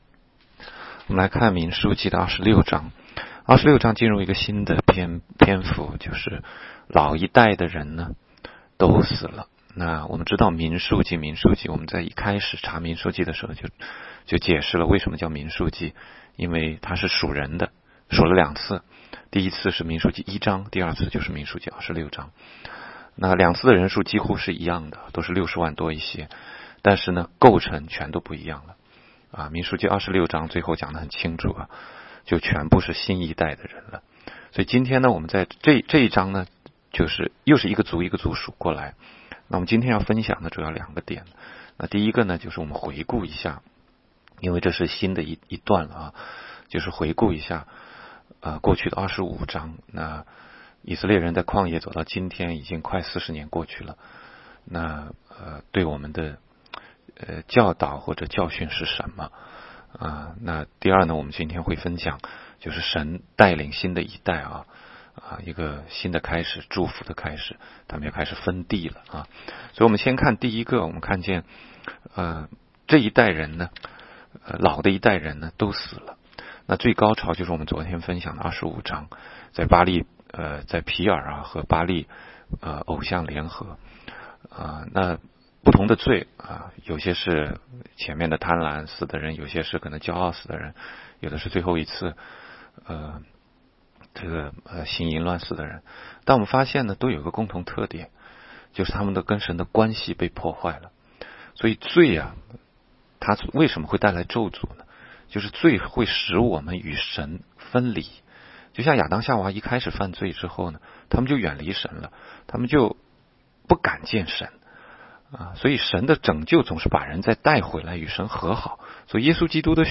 16街讲道录音 - 每日读经-《民数记》26章
每日读经